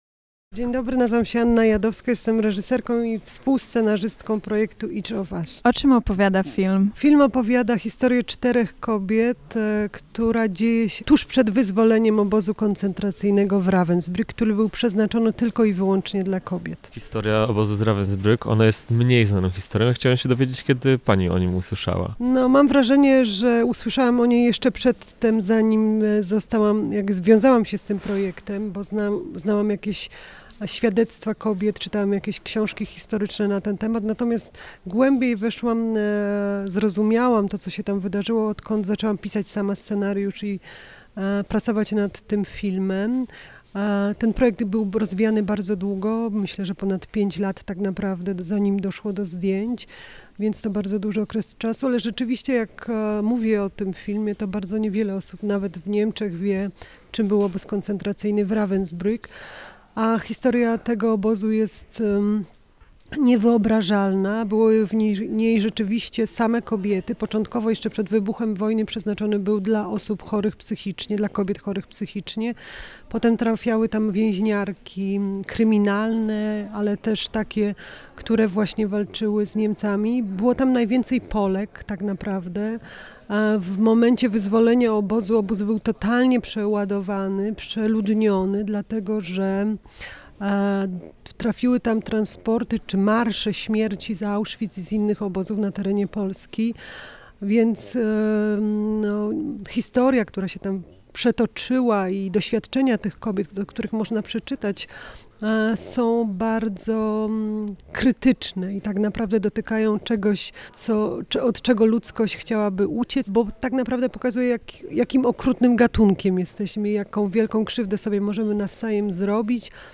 "Każda z nas" - rozmowa z twórczyniami międzynarodowej produkcji - Radio LUZ